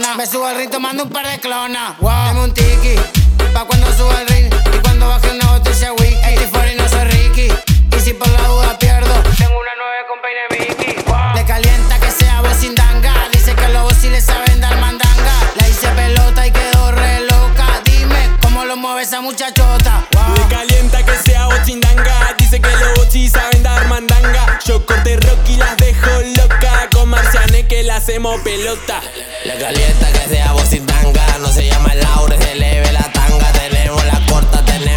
Urbano latino Latin Música tropical
Жанр: Латино